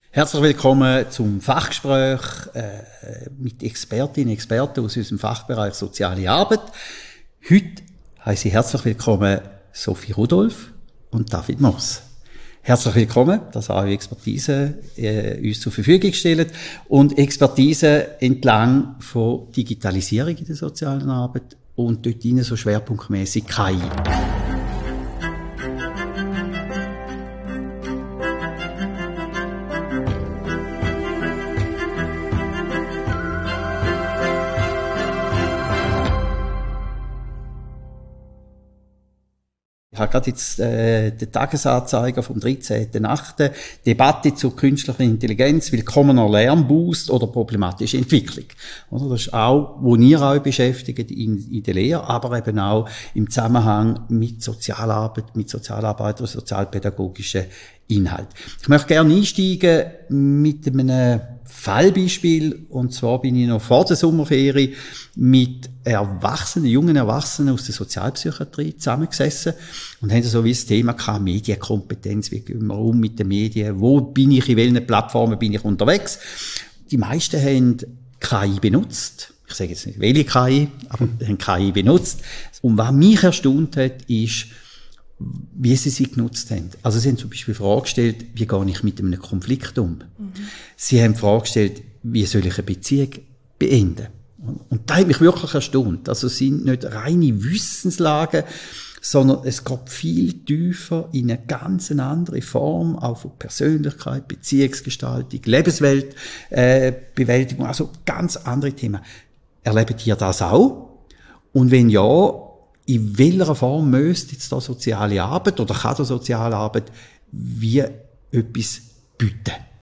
Fachgespräch